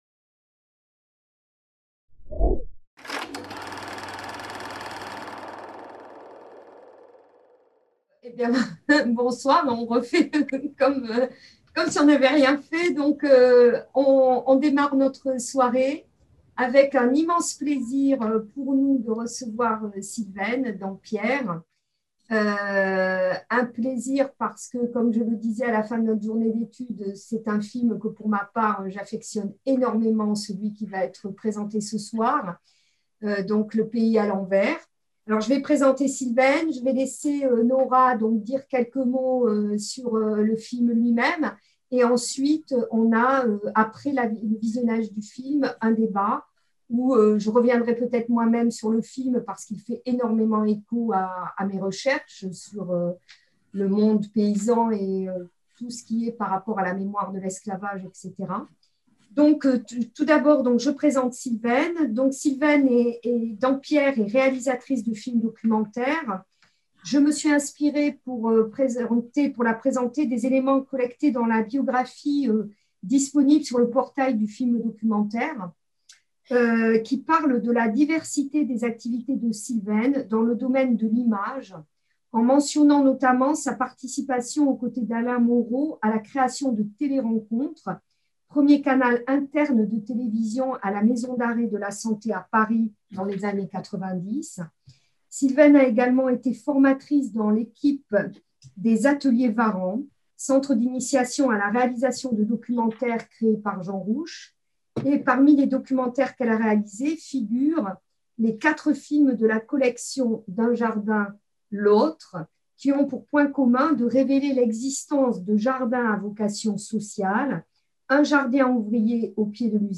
Soirée de clôture